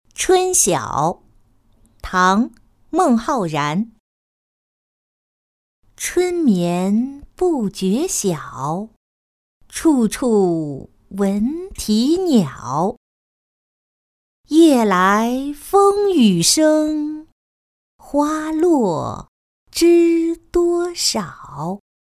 春晓-音频朗读